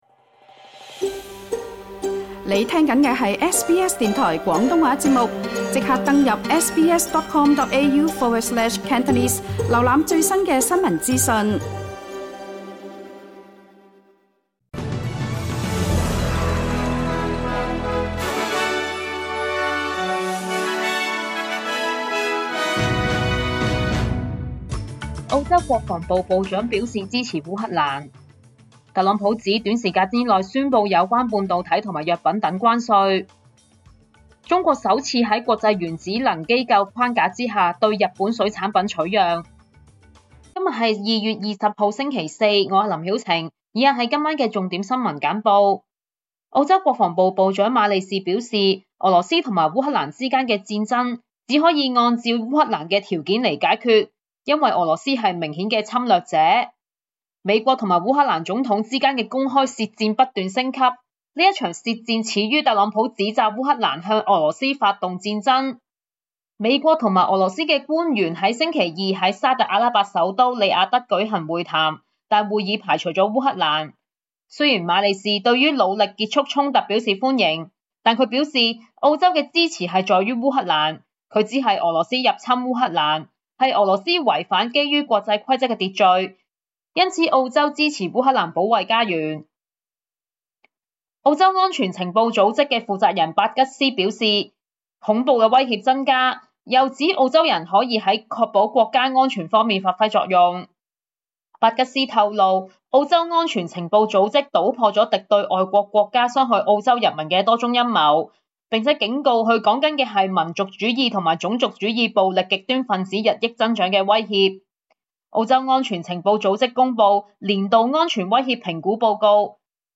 SBS晚間新聞（2025年2月20日）
請收聽本台為大家準備的每日重點新聞簡報。